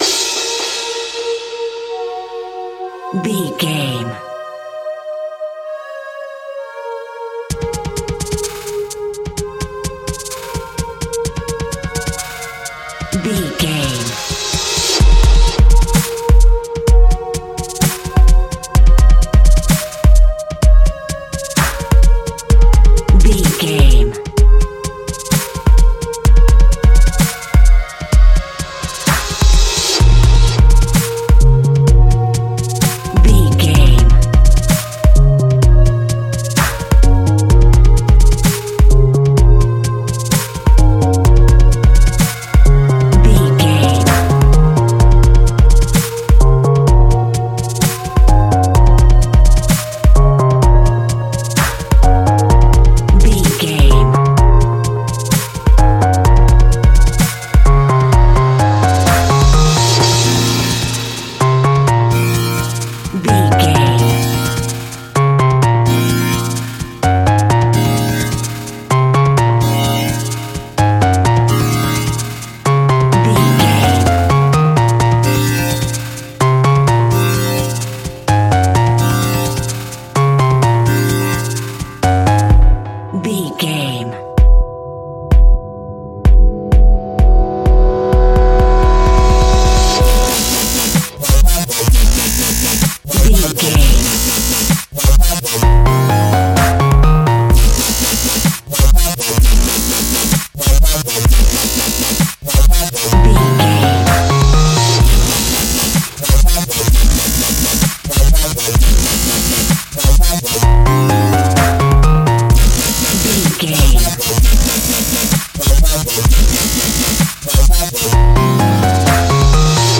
Epic / Action
Fast paced
Aeolian/Minor
B♭
aggressive
powerful
dark
driving
energetic
intense
synthesiser
drum machine
piano
breakbeat
synth leads
synth bass